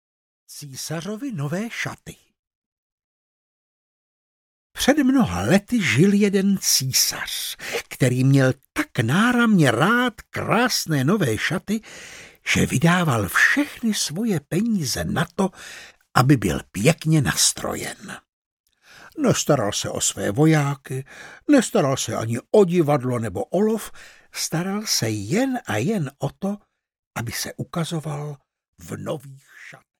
Císařovy nové šaty audiokniha
Ukázka z knihy